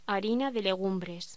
Locución: Harina de legumbres